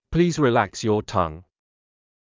ﾌﾟﾘｰｽﾞ ﾘﾗｯｸｽ ﾕｱ ﾀﾝｸﾞ